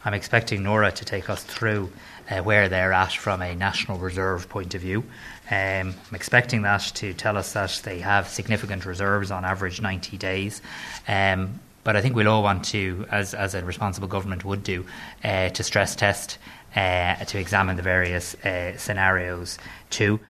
Tanaiste Simon Harris says they’ll get also get a briefing from NORA, the National Oil Reserves Agency……………